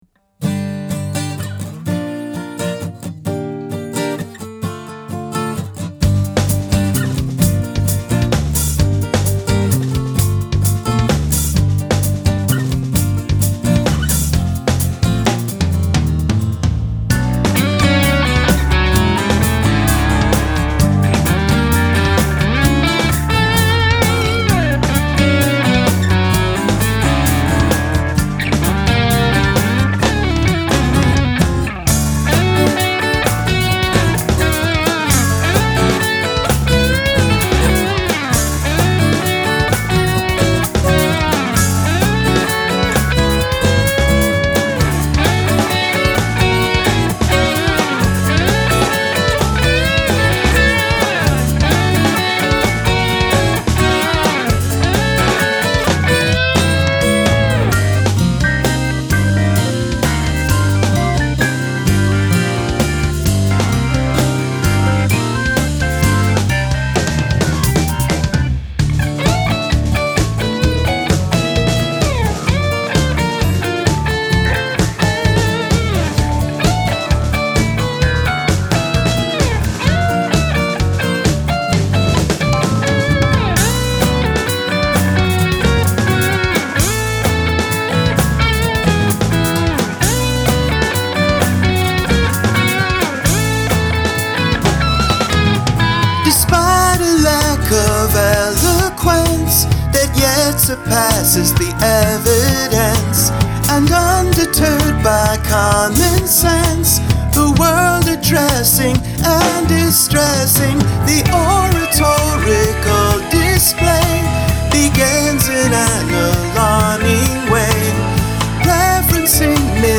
Lyric consists of only one sentence.